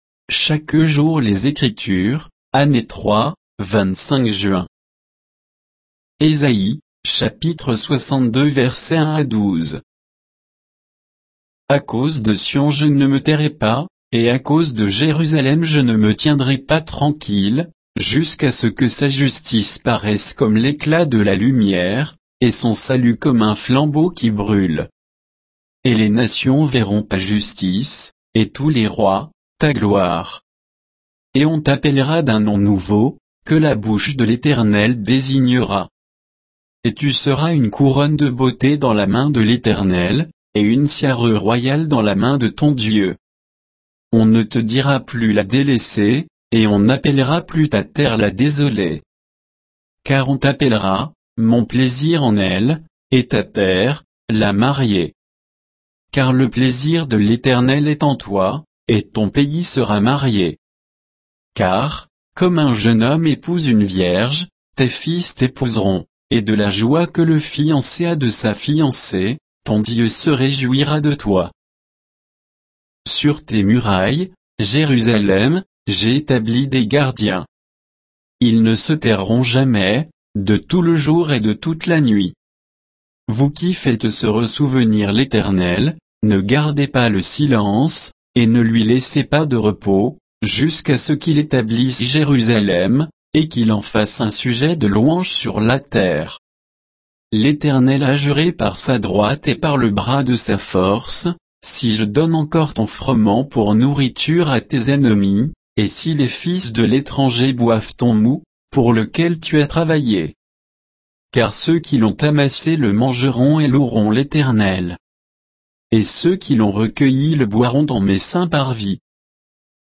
Méditation quoditienne de Chaque jour les Écritures sur Ésaïe 62